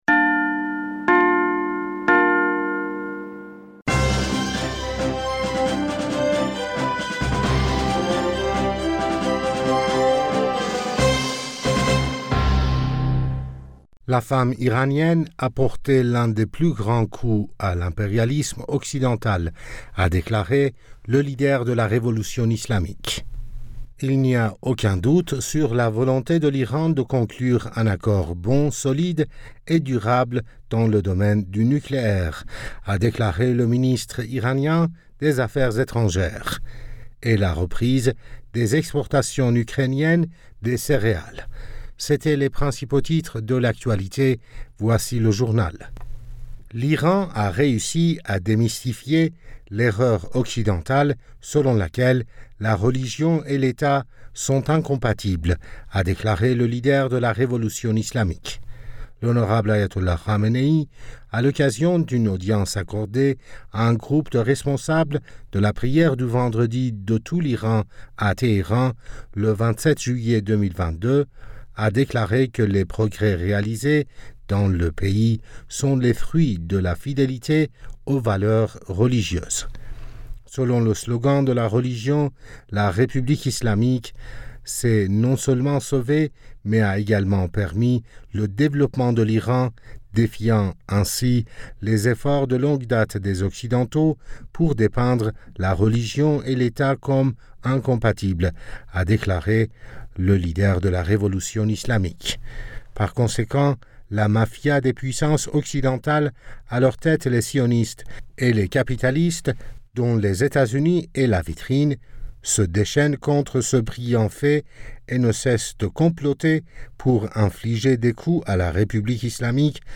Bulletin d'information Du 28 Julliet